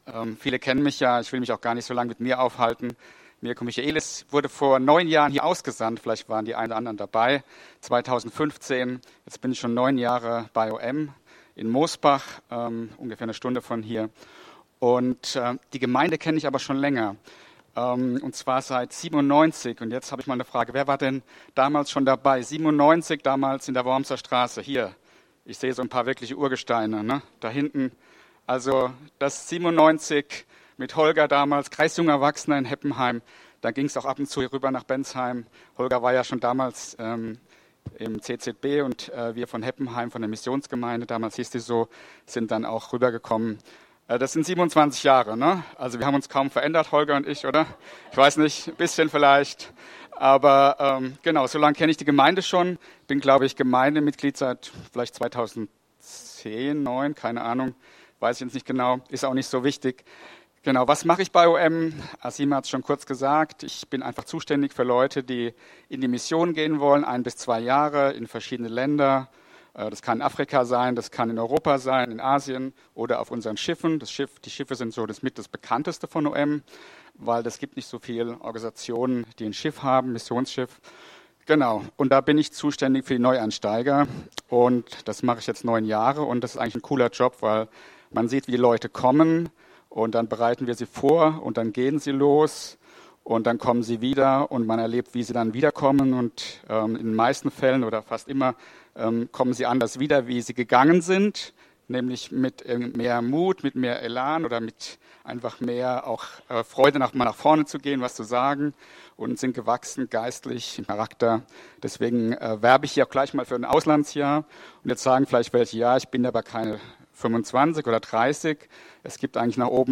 Eine allgemeine Predigt